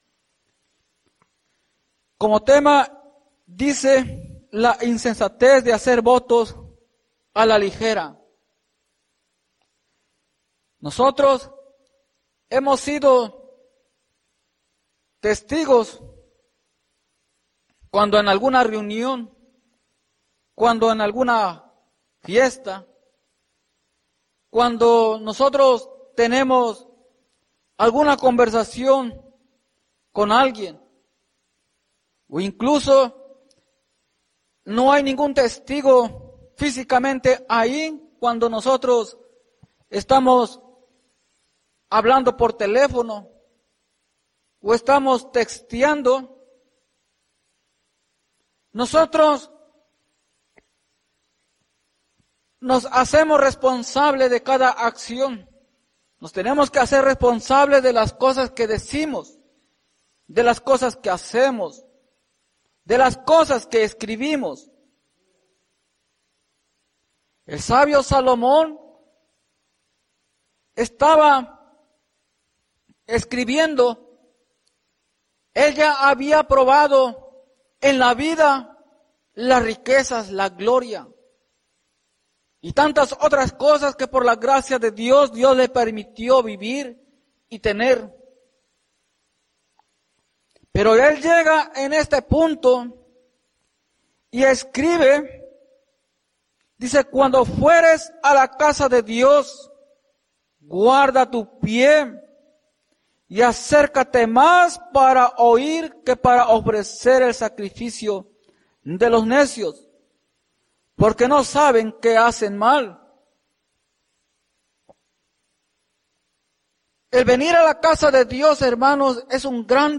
Atrapado Por Tus Palabras Predica